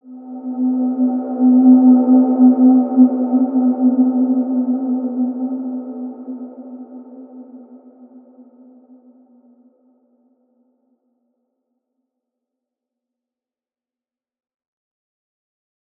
Large-Space-C4-mf.wav